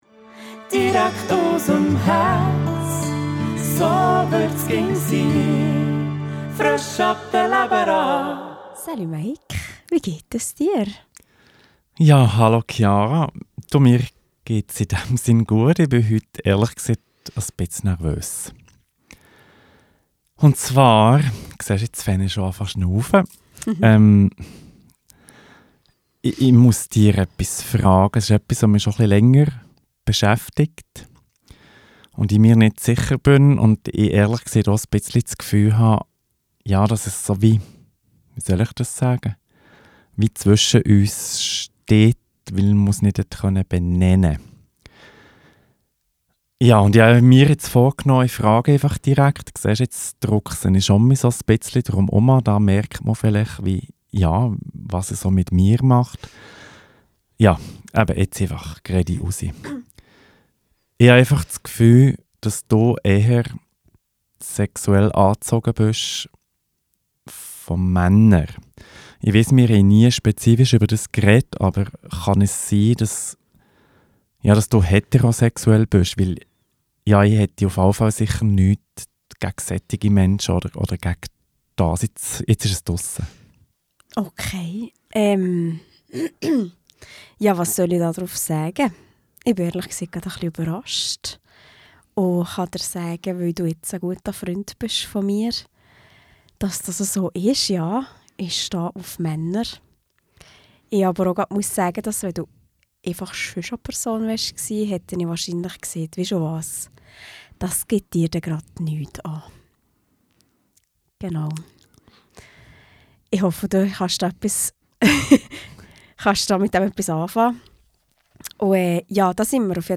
im Gspräch